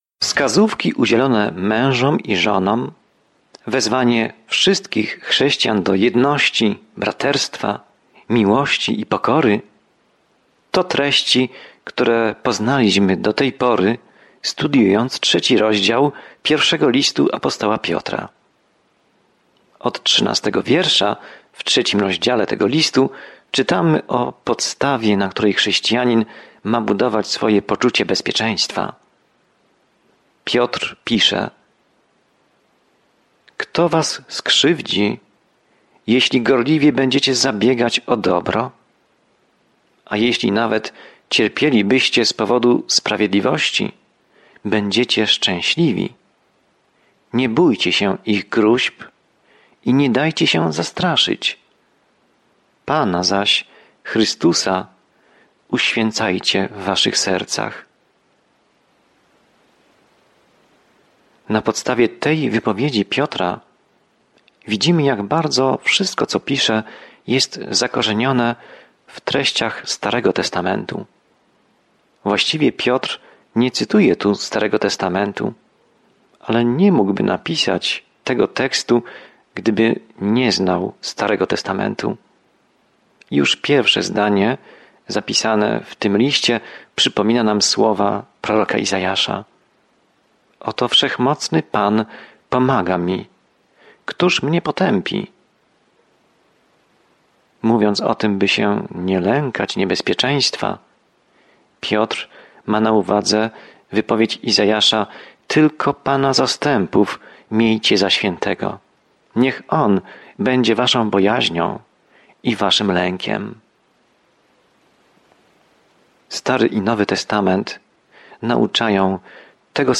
Pismo Święte 1 Piotra 3:13-18 Dzień 10 Rozpocznij ten plan Dzień 12 O tym planie Jeśli cierpisz dla Jezusa, ten pierwszy list Piotra zachęca cię, abyś podążał śladami Jezusa, który pierwszy cierpiał za nas. Codziennie podróżuj przez I List Piotra, słuchając studium audio i czytając wybrane wersety ze słowa Bożego.